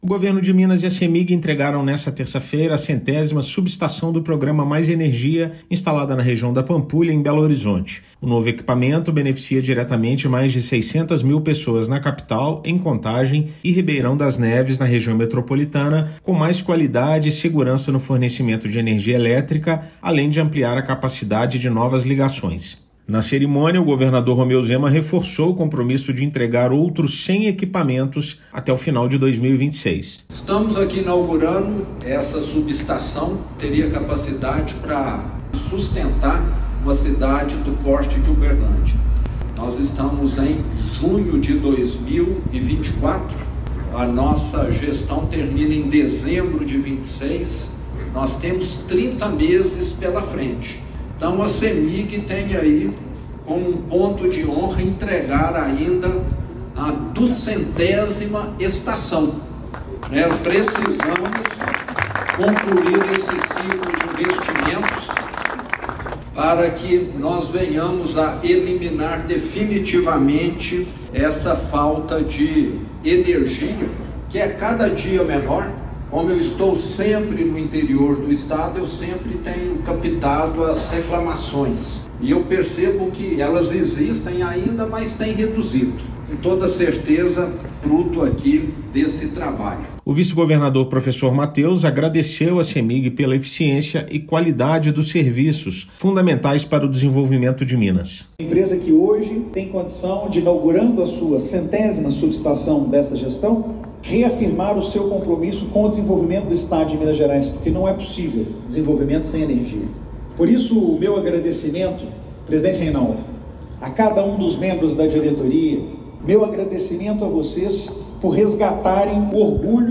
Desde 2021, programa já investiu R$ 2,7 bilhões nessas estruturas que ampliam e melhoram o fornecimento de energia para a população. Ouça a matéria de rádio: